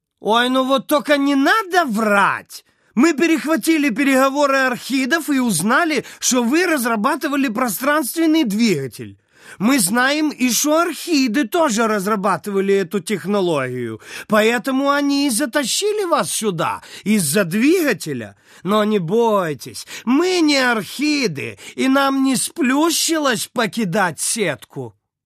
Примеры озвучания: